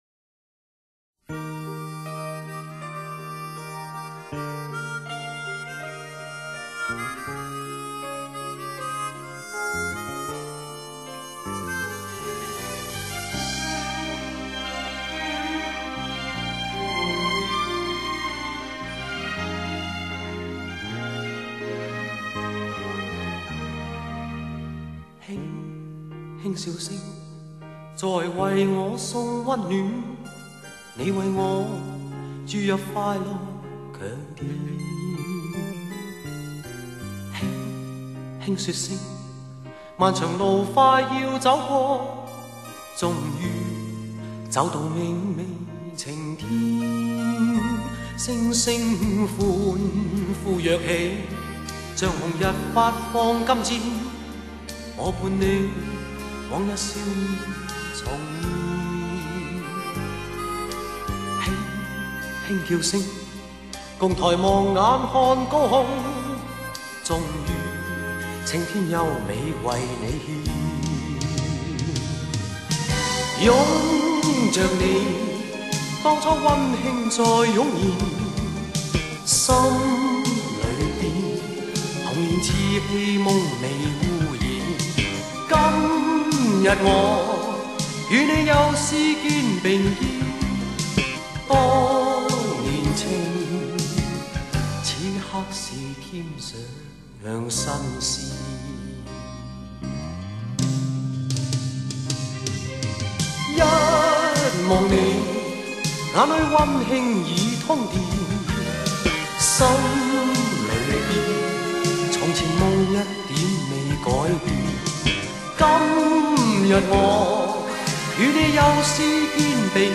他的缓缓而低沉的慢曲极易打动人心，而且除了少数的几首外，大都不是靠优美的旋律，而是因为歌词的质朴和歌者的演绎。